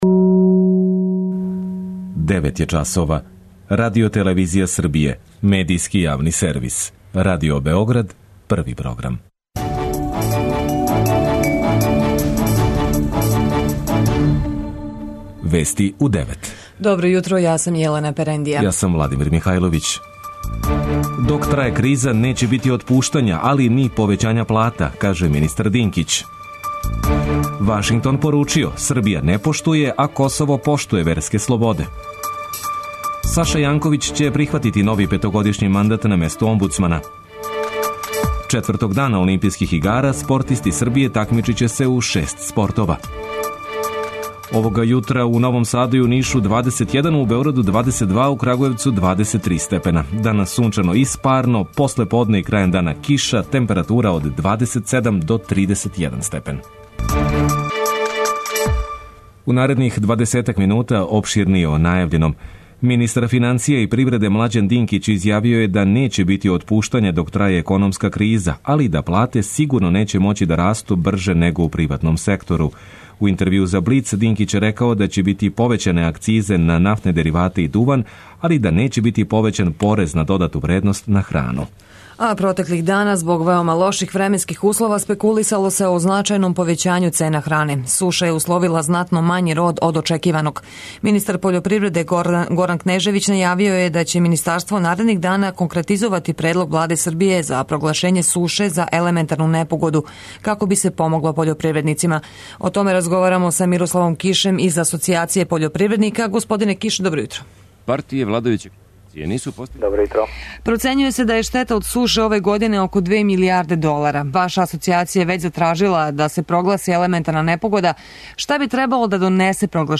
преузми : 9.28 MB Вести у 9 Autor: разни аутори Преглед најважнијиx информација из земље из света.